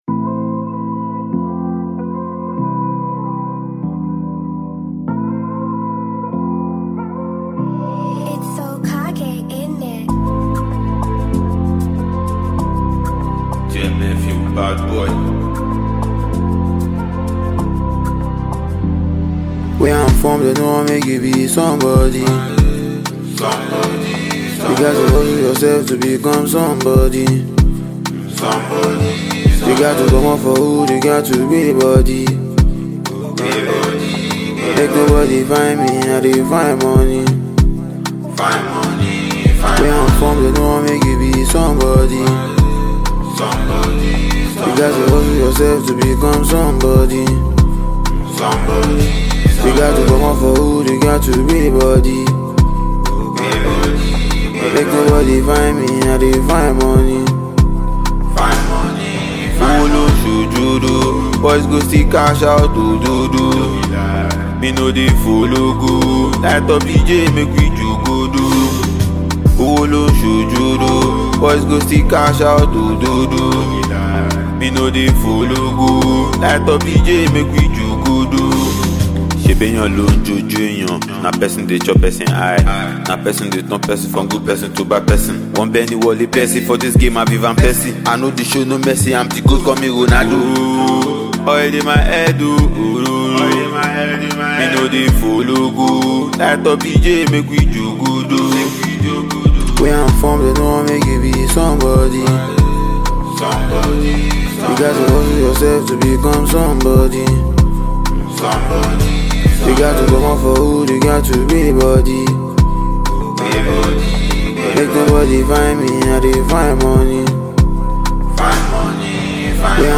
Talented Nigerian rapper
delivering powerful storytelling and captivating beats.
Afrobeats